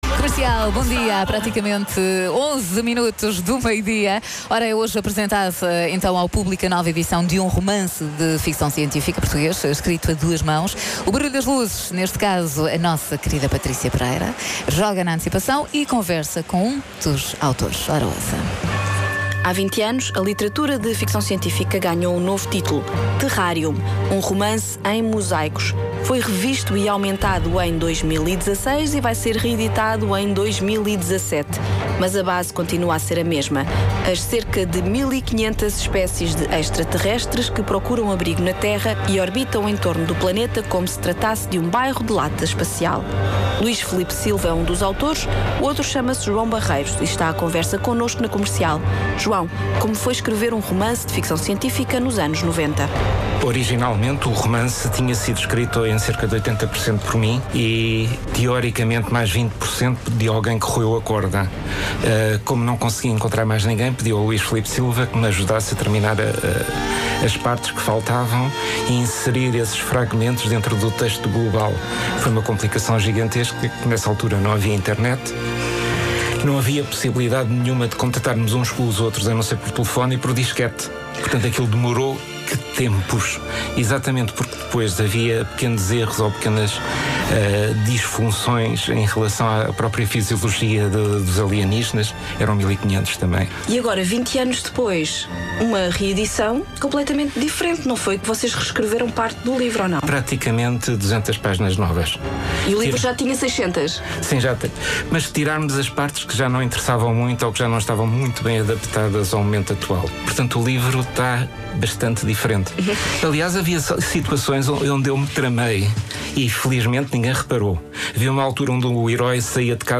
entrevista sobre o livro